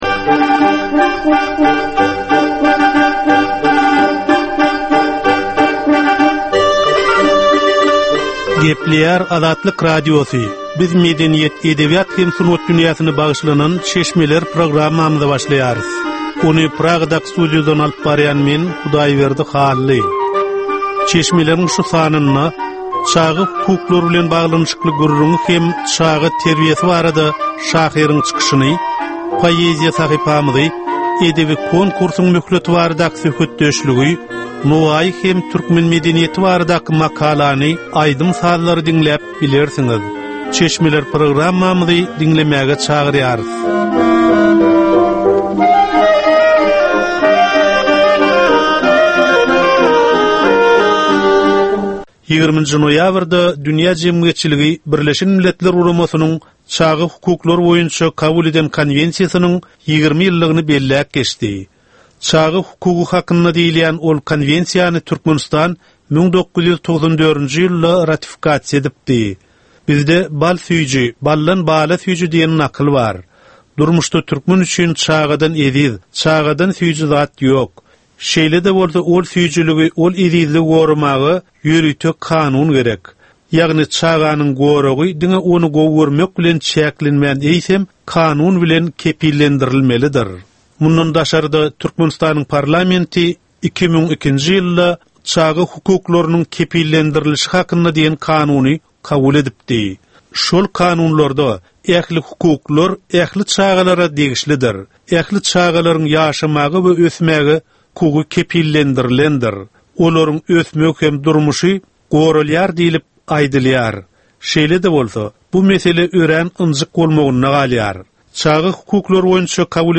Edebi, medeni we taryhy temalardan 25 minutlyk ýörite gepleşik.